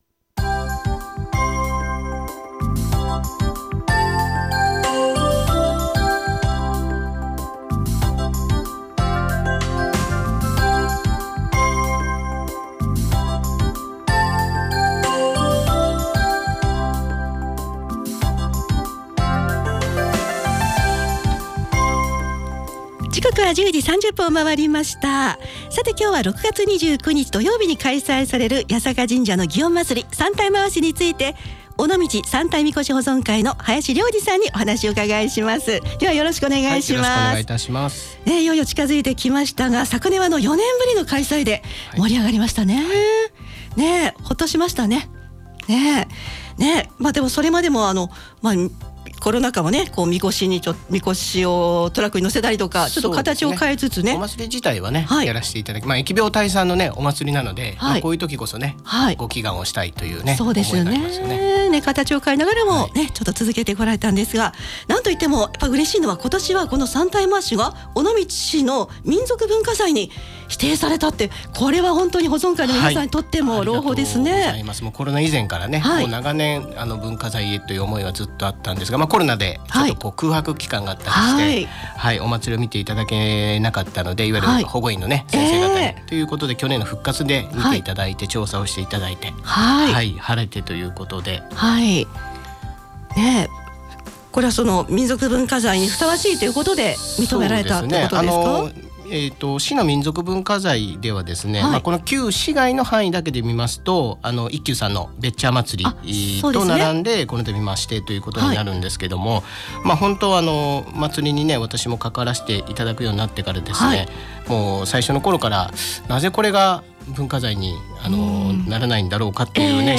歴史や今年の三体廻しについてお聞きしました。